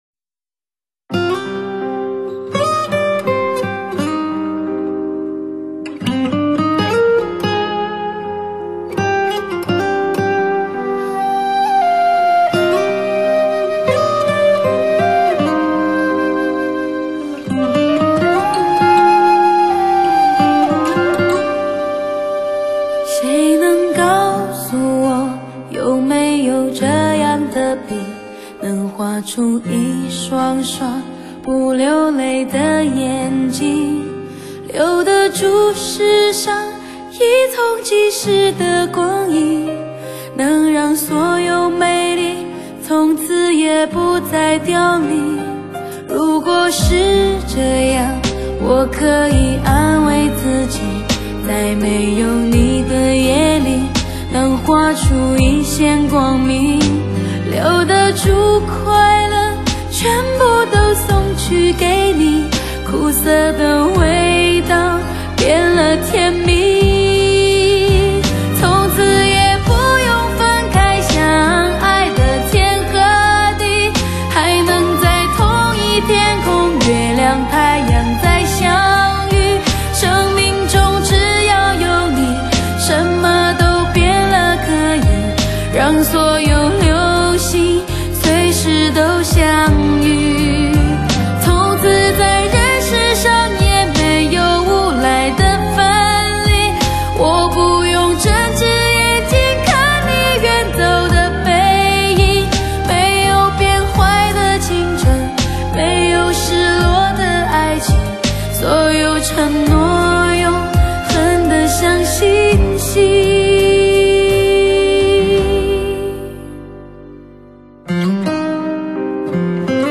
Surround7.1全方位，三维环绕HI-FI人声高解析，高临场天碟。
专有STS Magix 母带制作，STS magix virtual live高临场感CD。